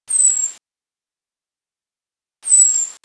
Erithacus rubecula - Robin - Pettirosso